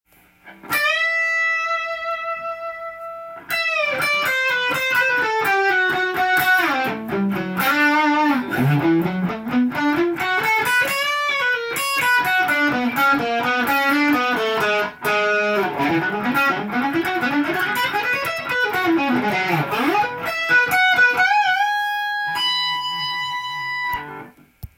ギターフレーズTAB譜
譜面通り弾いて弾いてみました
１：５５～のギターソロを耳コピしてみました。
オシャレコード進行のオンパレードです。
ポップな雰囲気も健在です。
ですのでkeyがGメジャーになっています。
機械的に弾いているようです。